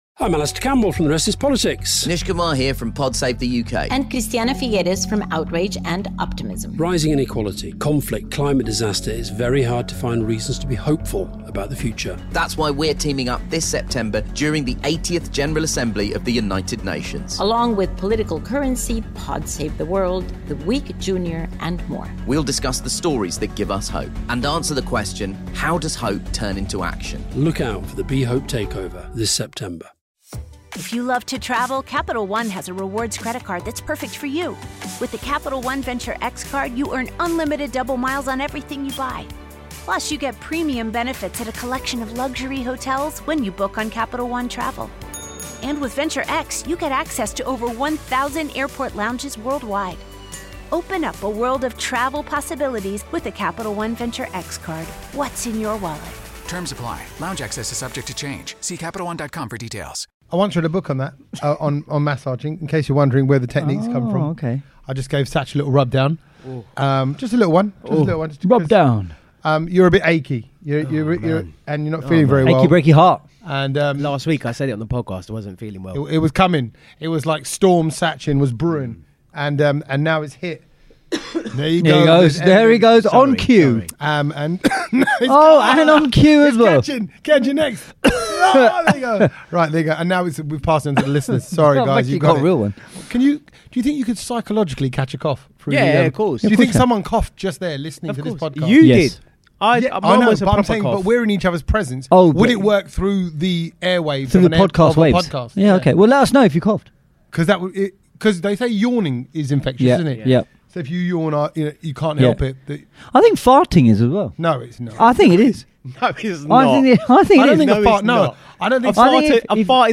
we’re all back together in the flesh at Kanchans restaurant in Ilford